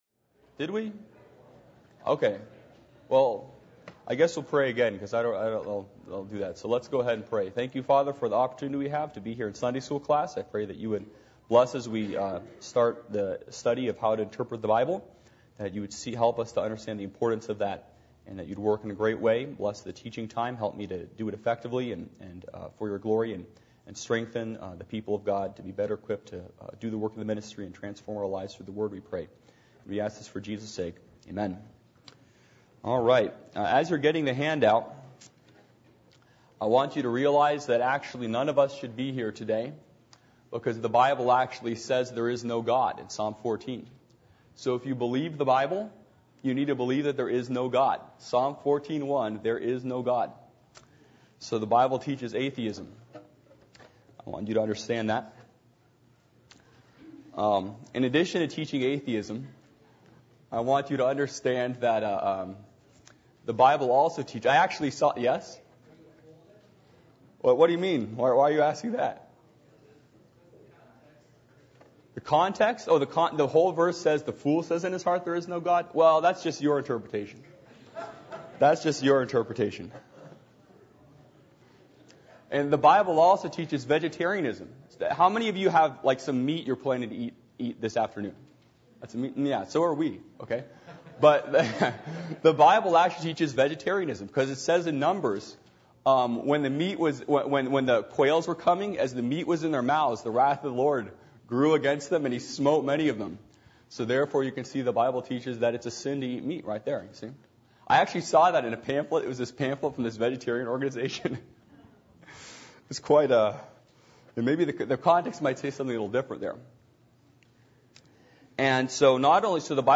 Adult Sunday School %todo_render% « Keys To The Local New Testament Church Sardis